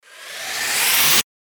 FX-982-WIPE
FX-982-WIPE.mp3